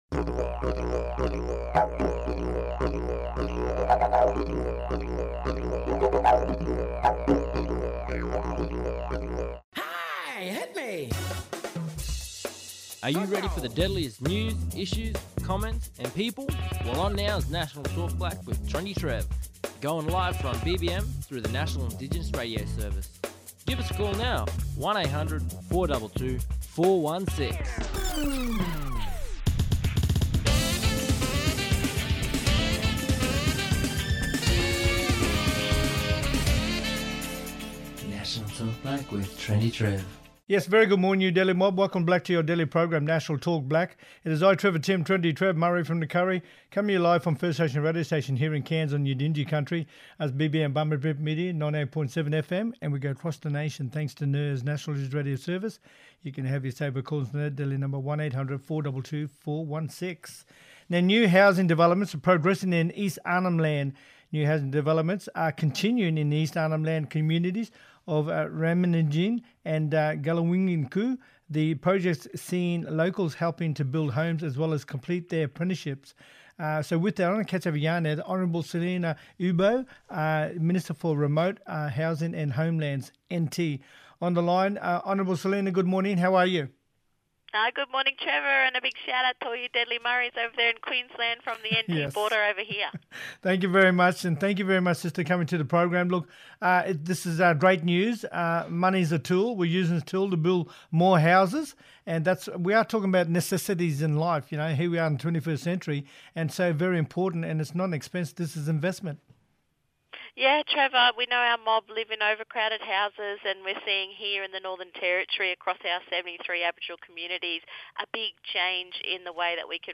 On todays National Talk Black via NIRS – National Indigenous Radio Service we have: